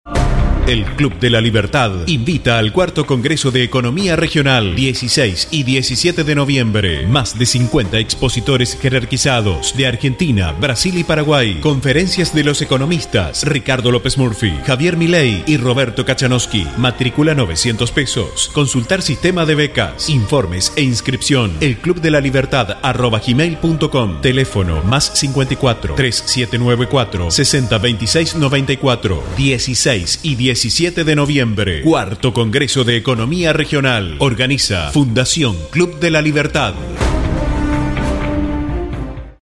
Spot Publicitario